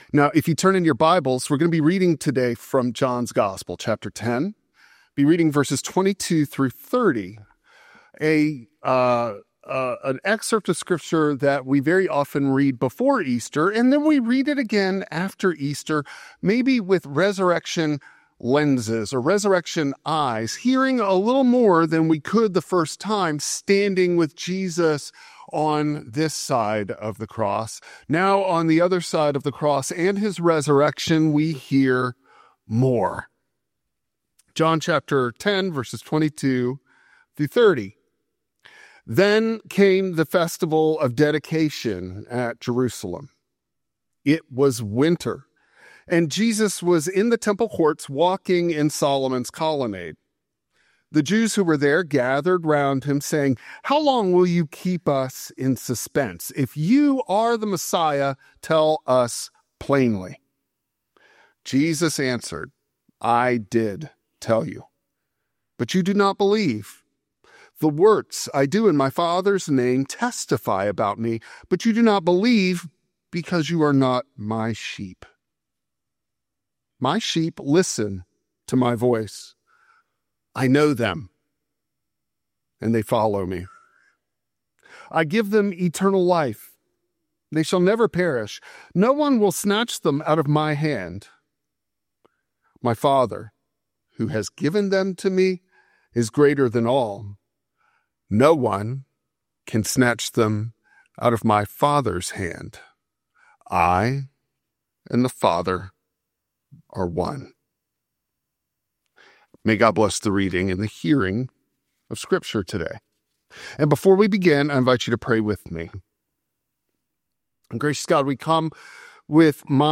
John 10:22-30 Service Type: Traditional Service Because Jesus lives